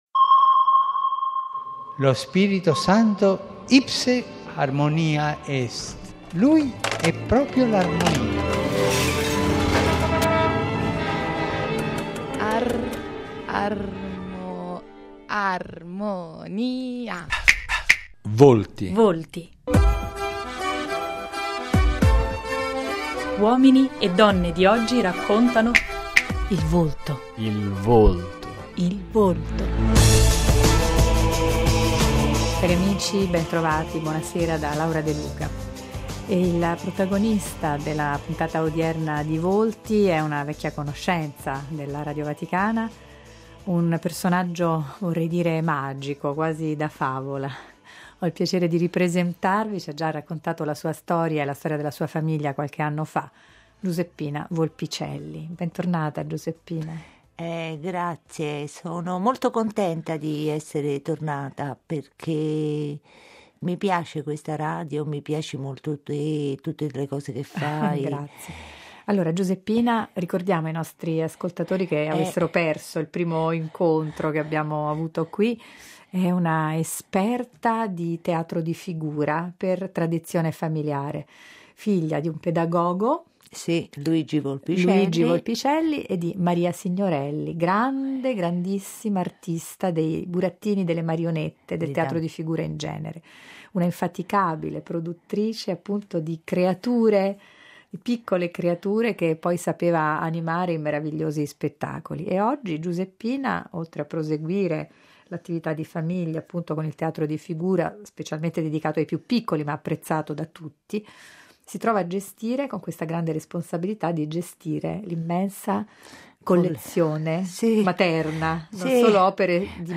si può riascoltare la nostra conversazione del 2012